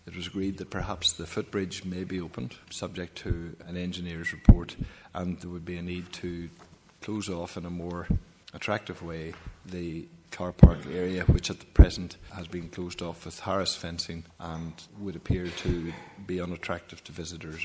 Councillor Robert Irvine: